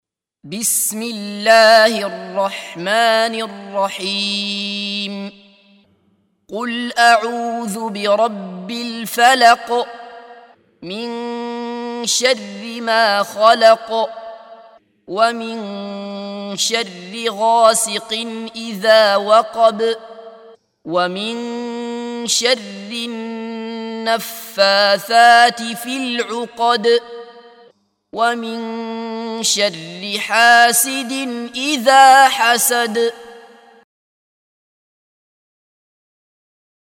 سُورَةُ الفَلَقِ بصوت الشيخ عبدالله بصفر